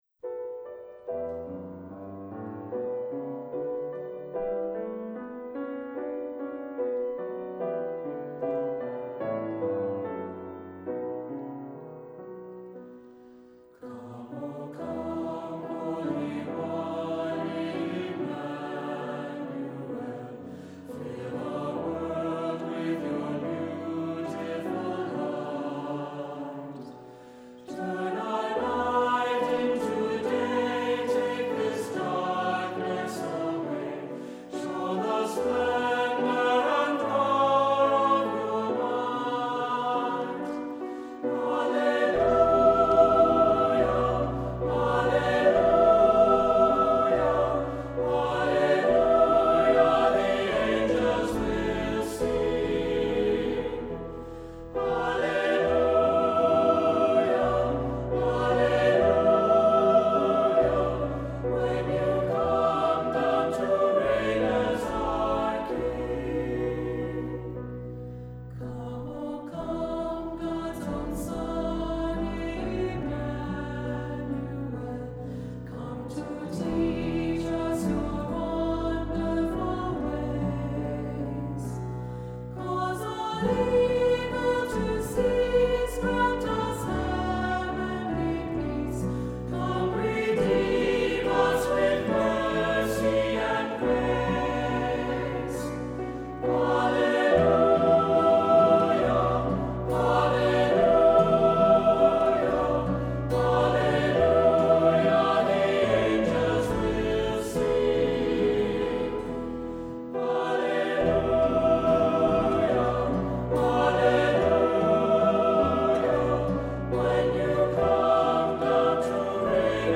Accompaniment:      Keyboard
Music Category:      Christian
SATB, Guitar, Acc.